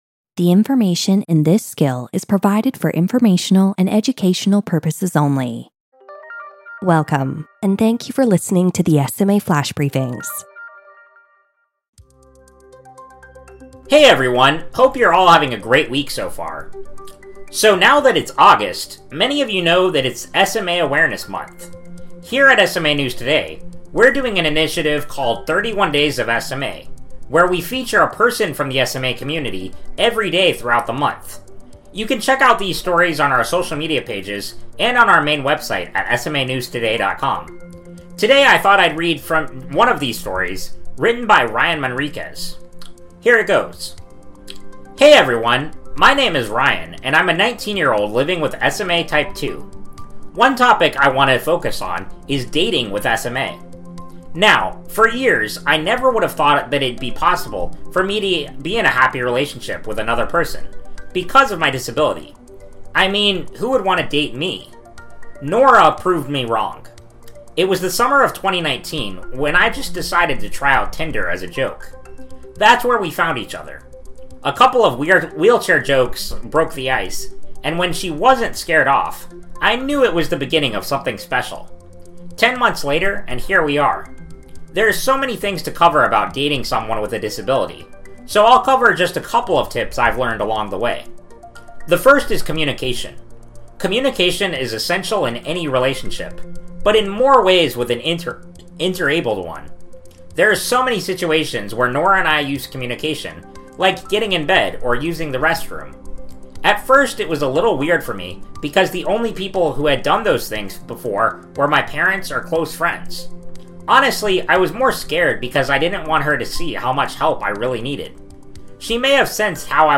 reads from one of the stories from our 31 Days of SMA initiative. This story focuses on dating and disability.